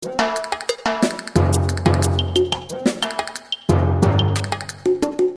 管弦乐打击乐 90
描述：MIDI音轨与管弦乐鼓组的录制
Tag: 90 bpm Classical Loops Percussion Loops 922.75 KB wav Key : Unknown